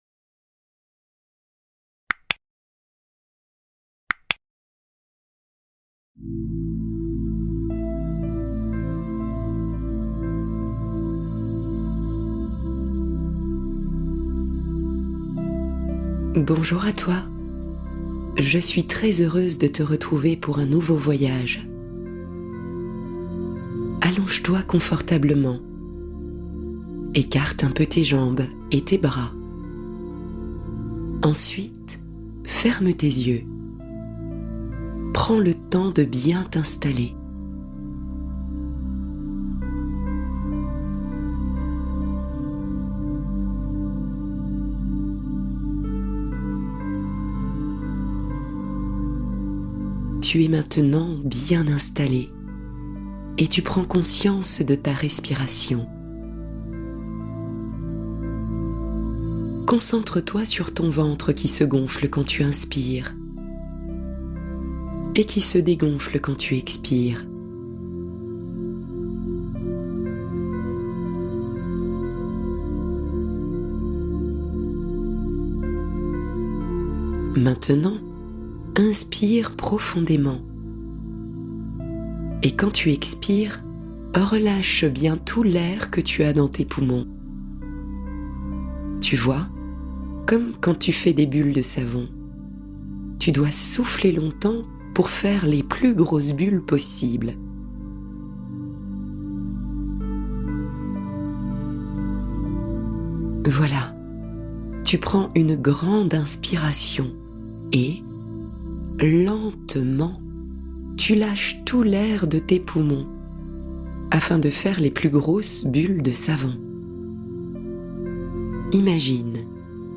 L'Aventure de Lili : Conte apaisant pour stimuler l'imaginaire